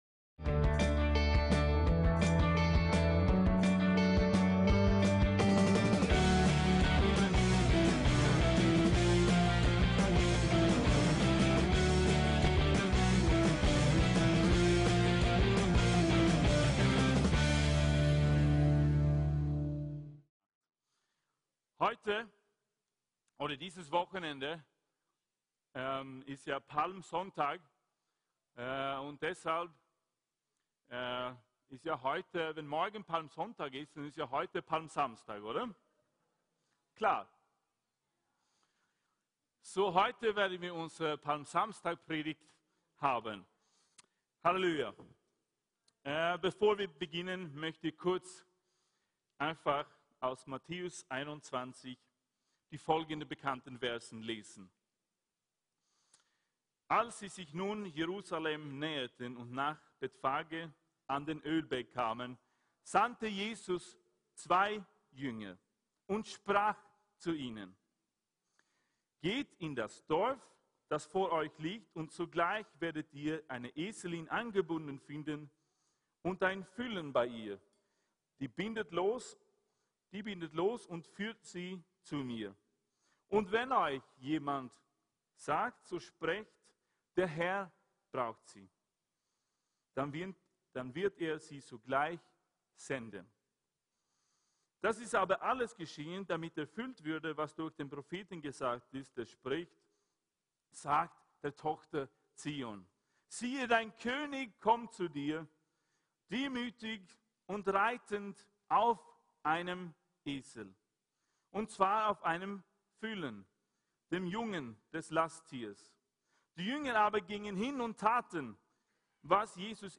VCC JesusZentrum Gottesdienste